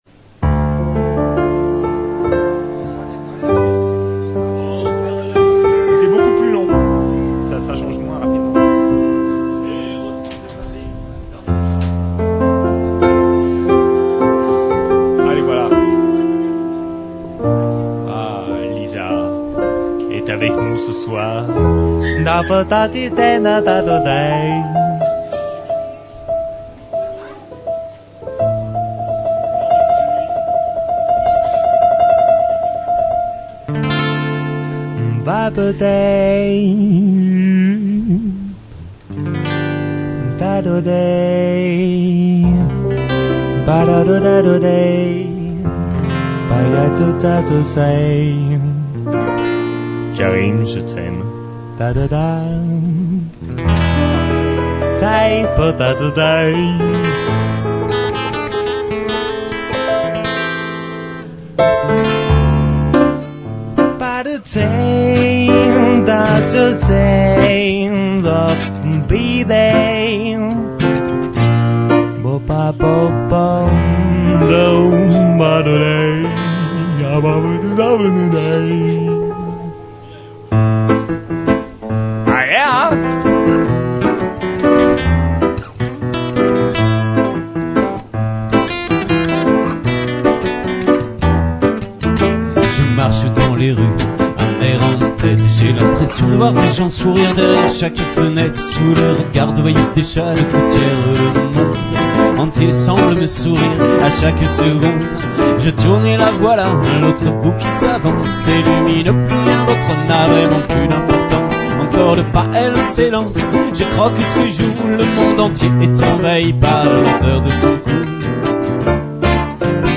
live